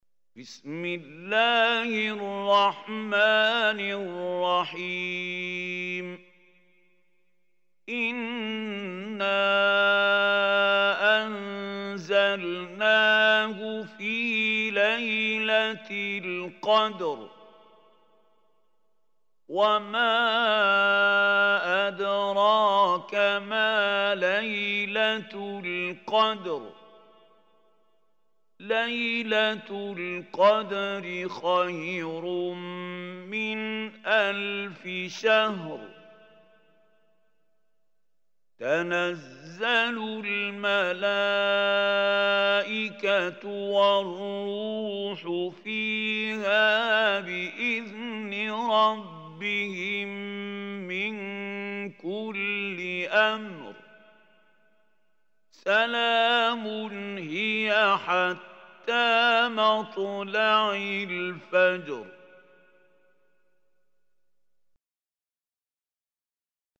Surah Al-Qadr Recitation by Sheikh Mahmoud Hussary